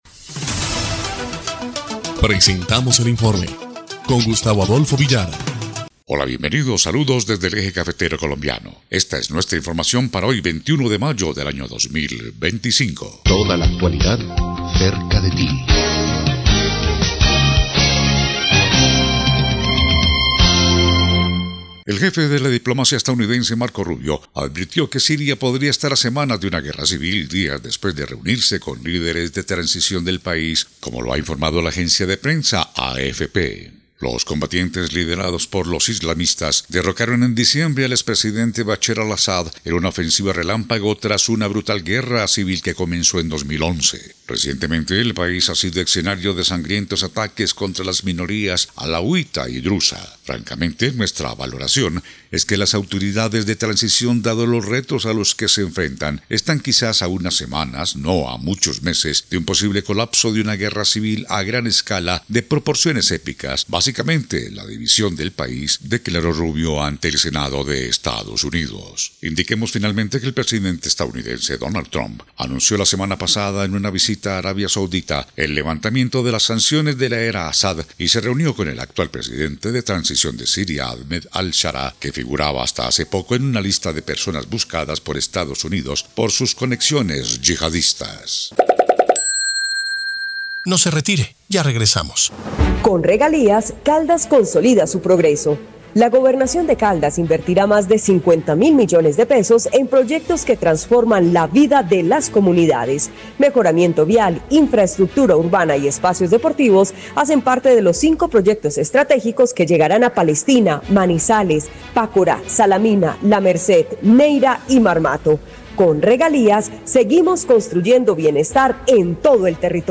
EL INFORME 1° Clip de Noticias del 21 de mayo de 2025